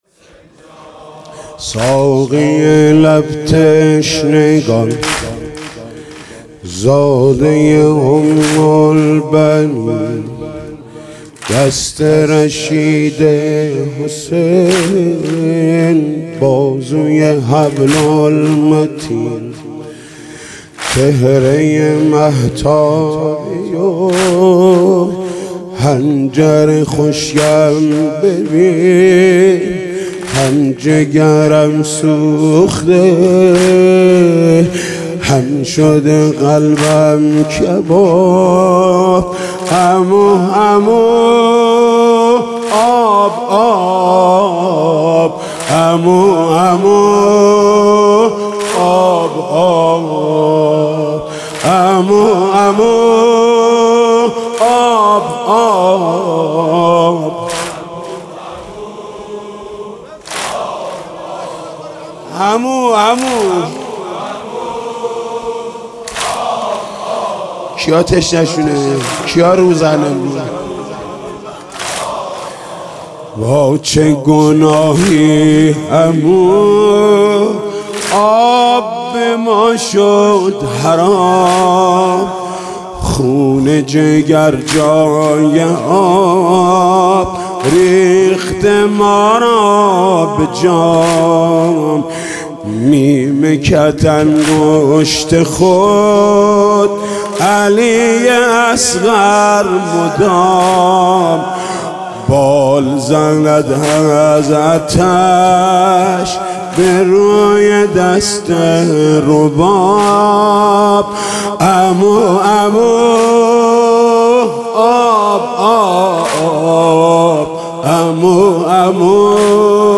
«محرم 1396» (شب تاسوعا) واحد: ساقی لب تنشگان
«محرم 1396» (شب تاسوعا) واحد: ساقی لب تنشگان خطیب: حاج محمود کریمی مدت زمان: 00:06:21